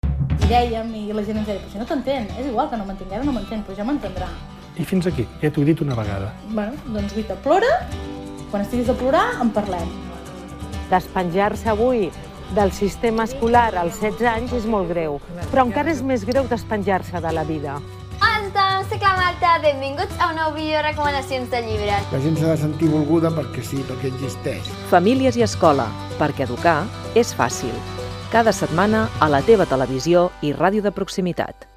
Promoció del programa produït per La Xarxa
Banda FM